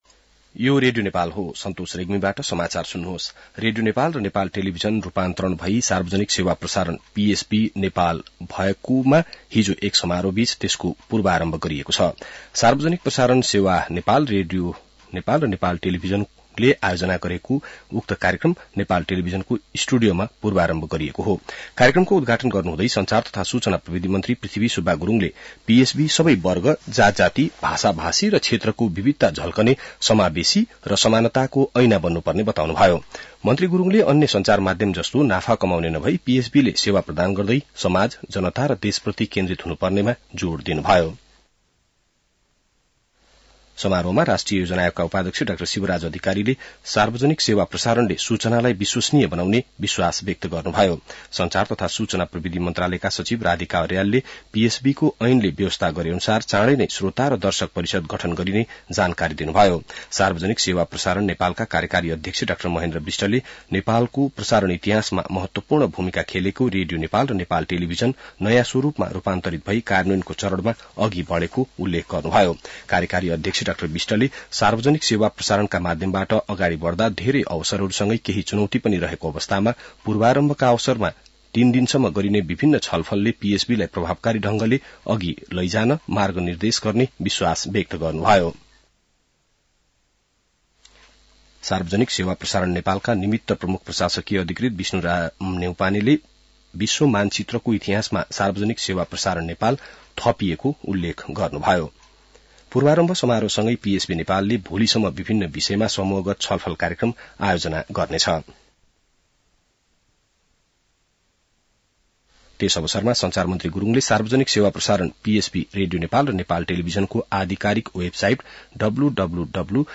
बिहान ६ बजेको नेपाली समाचार : ३ माघ , २०८१